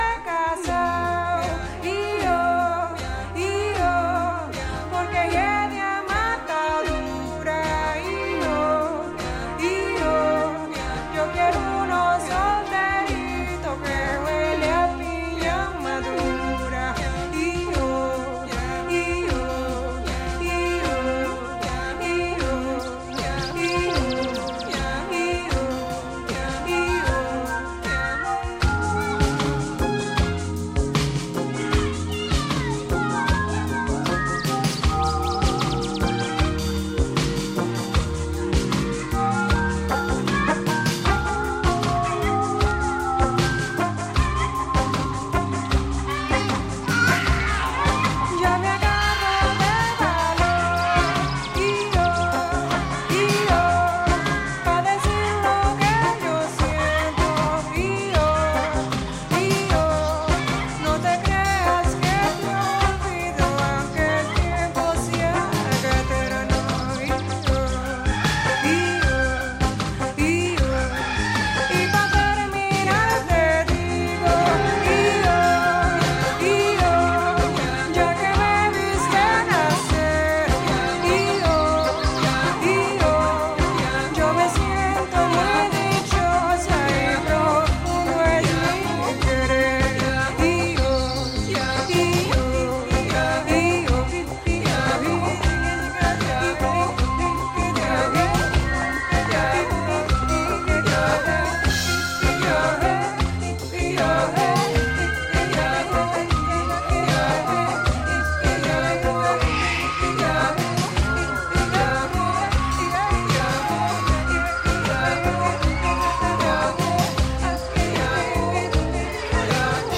Disco
Indie dance, Italo